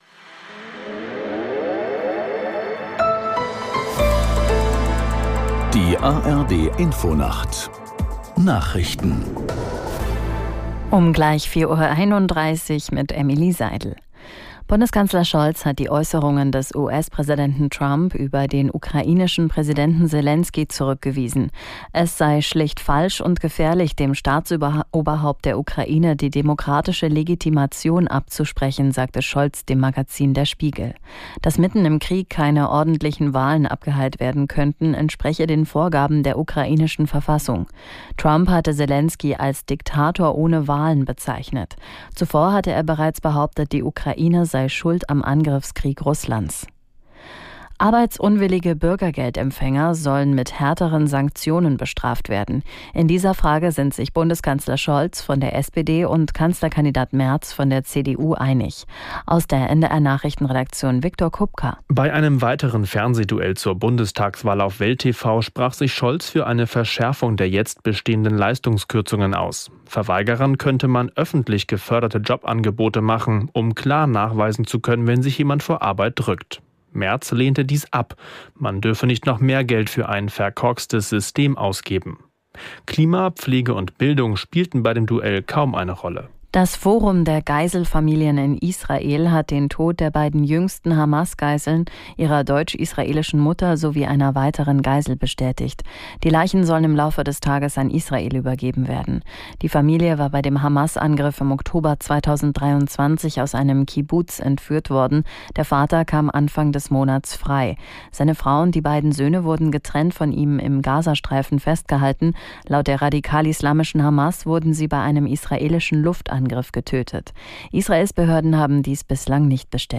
ไม่ต้องลงทะเบียนหรือติดตั้ง Nachrichten.